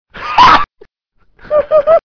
laugh3